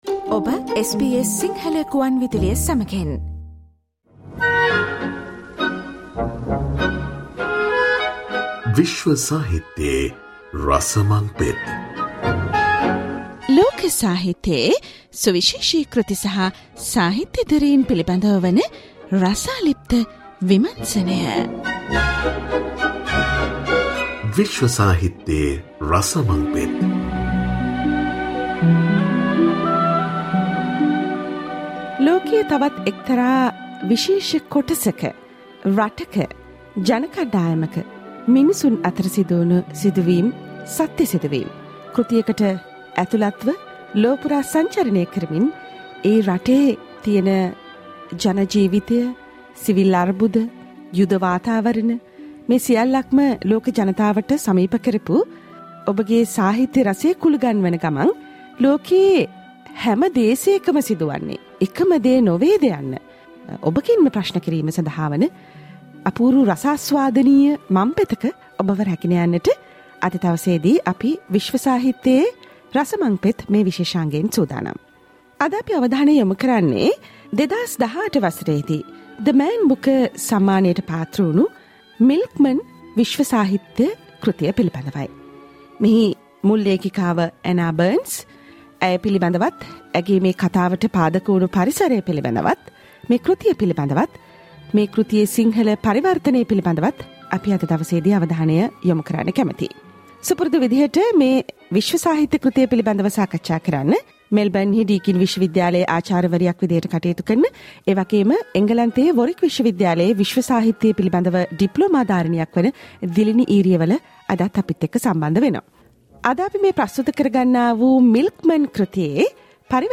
SBS Sinhala radio World Literary discussion